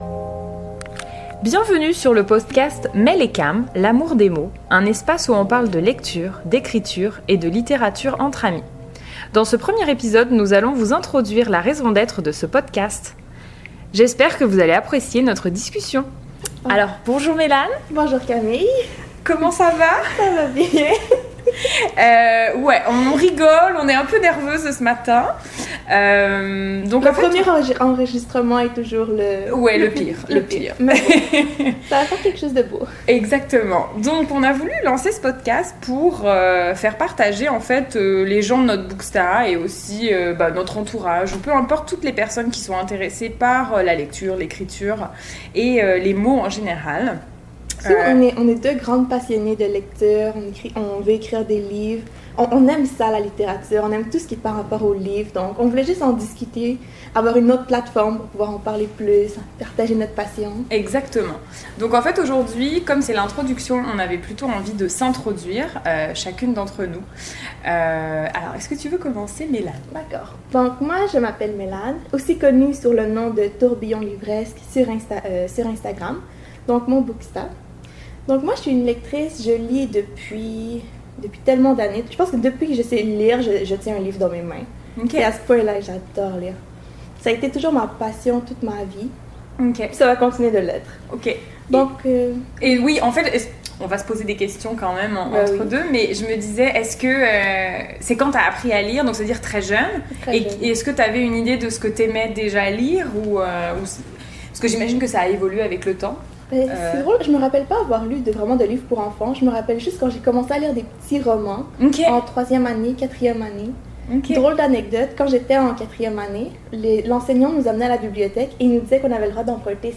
Bienvenue sur le Podcast L’amour des mots, un espace où on parle de lecture, d’écriture et de littérature entre amies.